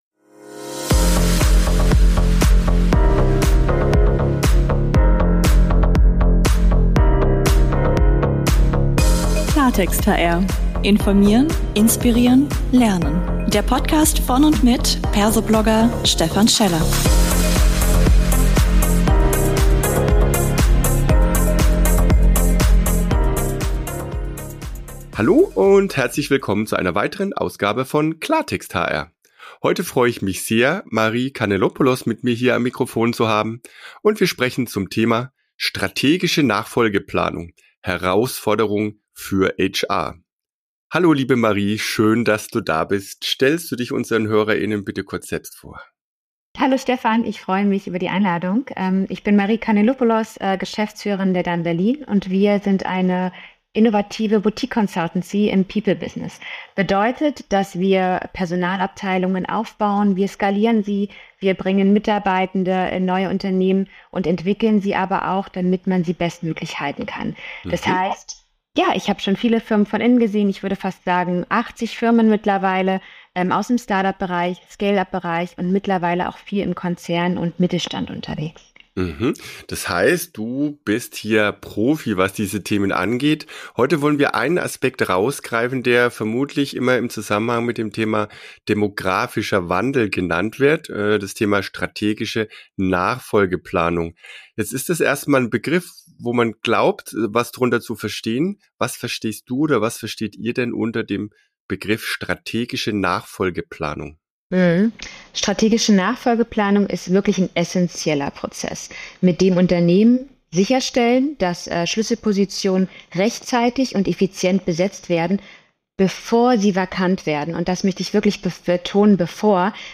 Ein spannender Talk als 15-Minuten-Impuls.